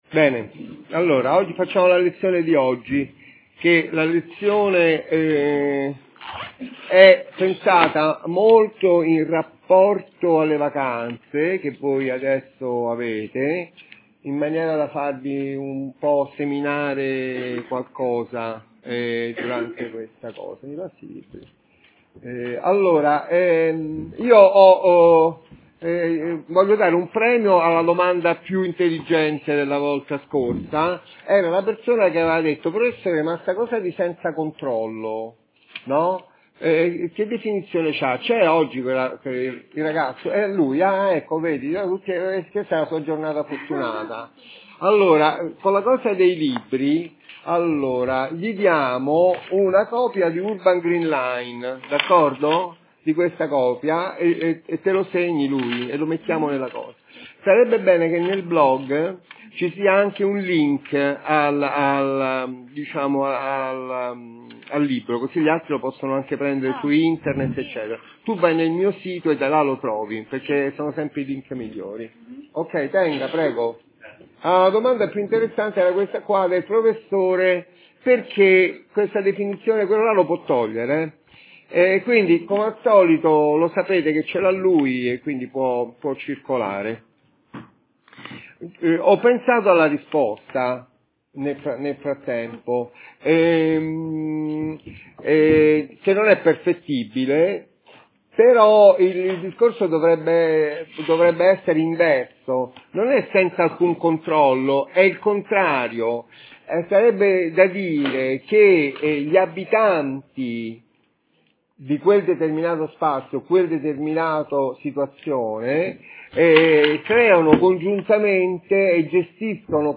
8º Lezione: 6 Aprile: "il Bang" : Sperimentazione progettuale: la Scacchiera